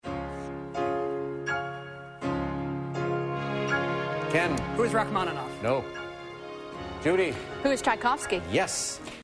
It's this Russian composer's Piano Concerto No. 1 you're listening to